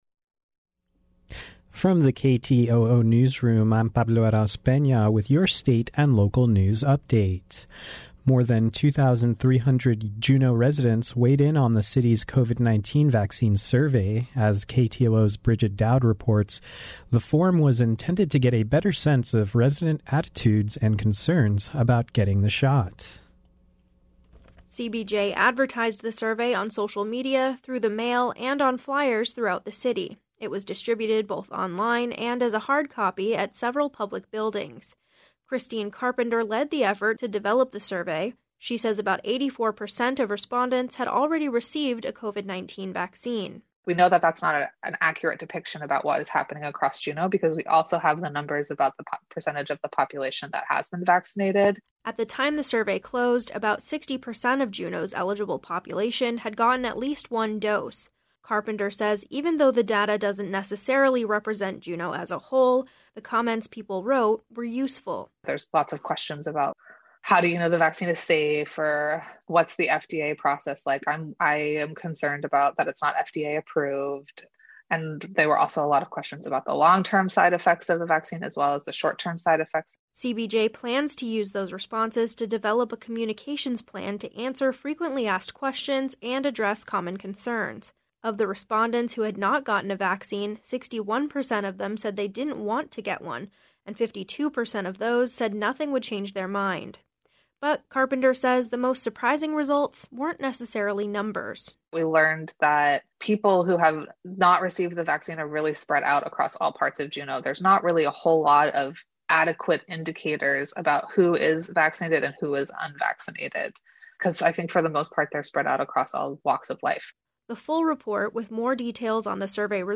KTOO News Update The day’s local and state news in about 10 minutes.